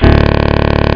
sounds / clicks / saxbutt.wav
saxbutt.mp3